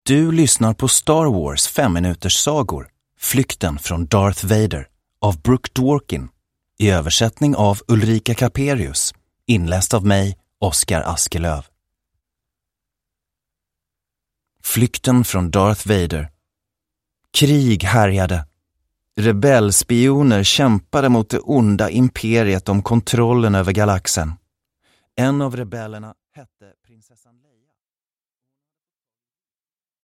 Flykten från Darth Vader. Fjärde berättelsen ur Star Wars 5-minuterssagor – Ljudbok – Laddas ner